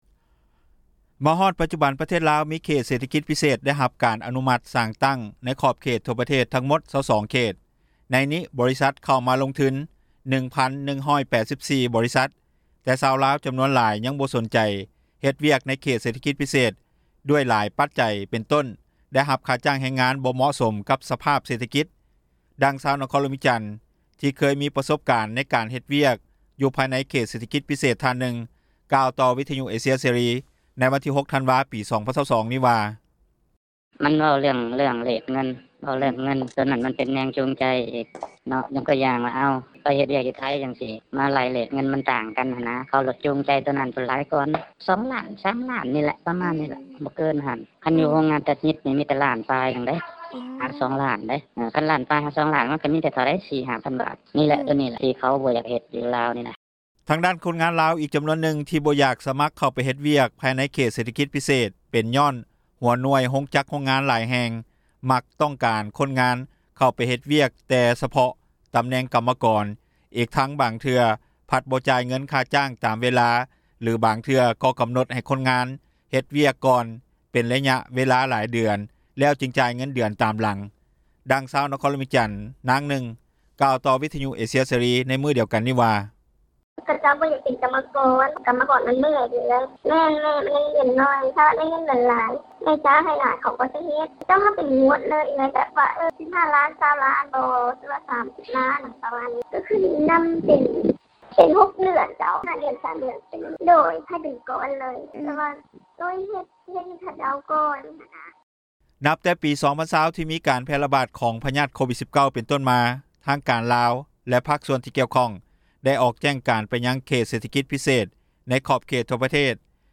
ດັ່ງຊາວນະຄອນຫລວງວຽງຈັນ ທີ່ເຄີຍມີປະສົບການ ໃນການເຮັດວຽກ ຢູ່ພາຍໃນເຂດເສຖກິຈ ພິເສດທ່ານນຶ່ງ ກ່າວຕໍ່ວິທຍຸ ເອເຊັຽ ເສຣີ ໃນວັນທີ 06 ທັນວາ 2022 ນີ້ວ່າ:
ດັ່ງຊາວນະຄອນຫລວງວຽງຈັນ ນາງນຶ່ງກ່າວຕໍ່ວິທຍຸ ເອເຊັຽ ເສຣີ ໃນມື້ດຽວກັນນີ້ວ່າ:
ດັ່ງເຈົ້າໜ້າທີ່ ທີ່ເຮັດວຽກກ່ຽວກັບ ການຄຸ້ມຄອງຄົນງານ ພາຍໃນປະເທດ ຜູ້ບໍ່ປະສົງອອກຊື່ ແລະຕຳແໜ່ງທ່ານນຶ່ງກ່າວວ່າ: